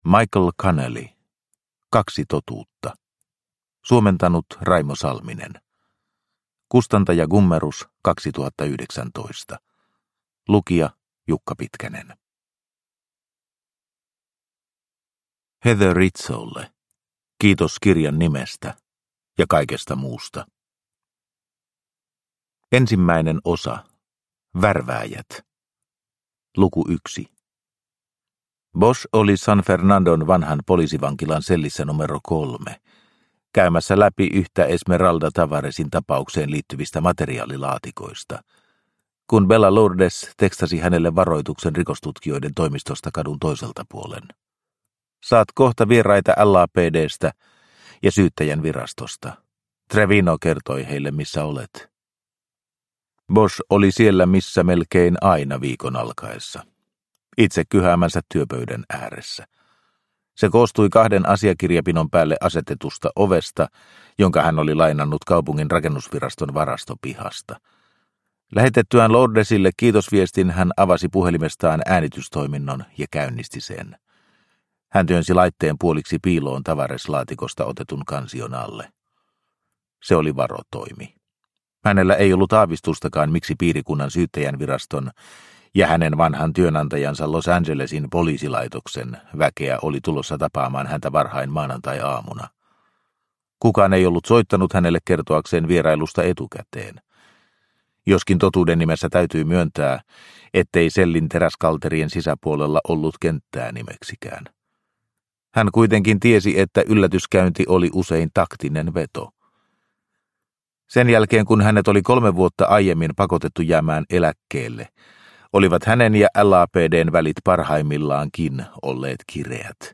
Kaksi totuutta – Ljudbok – Laddas ner